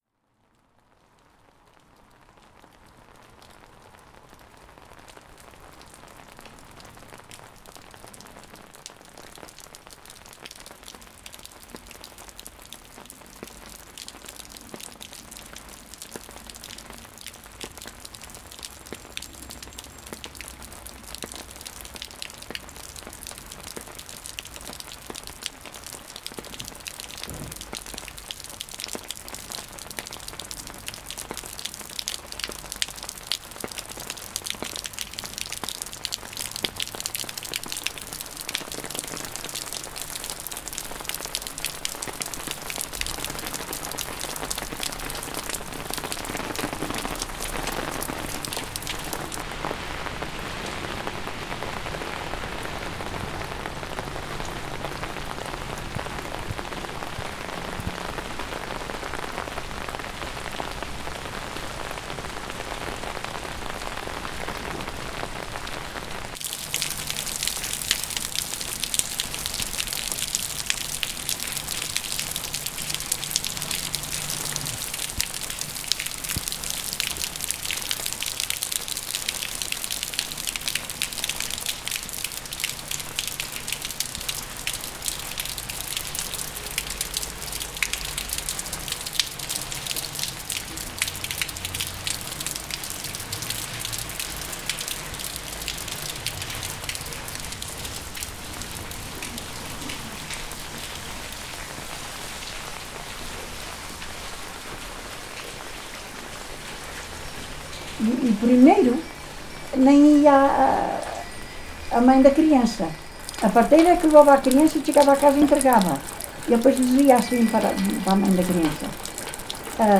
Tipo de Prática: Arte Sonora